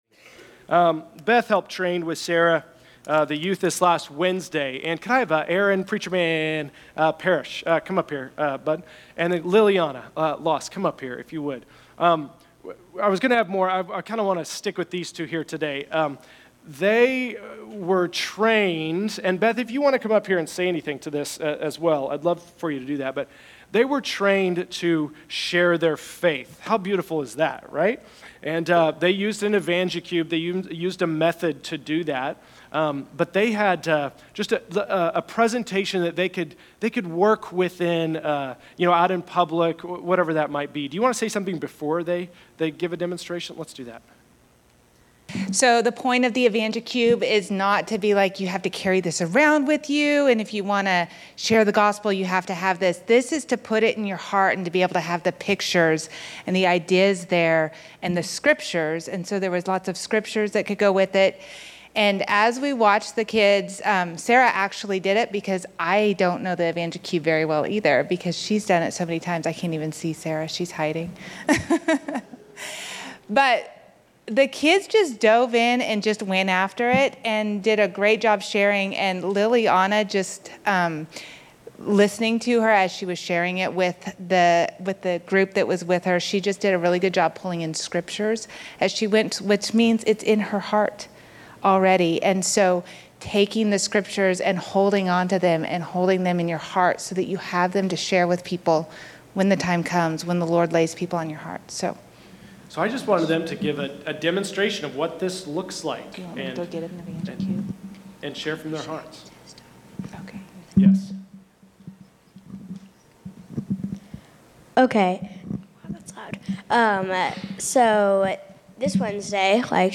October 15, 2023      |     By: Resonate Youth      |      Category: Testimonies      |      Location: El Dorado
Two of our youth share the Gospel through use of the Evangicube and being set free from fear of man.